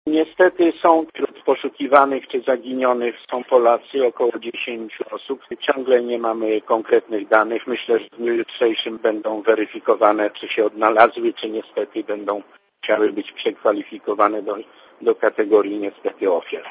Mówi ambasador RP w Tajlandii, Bogdan Góralczyk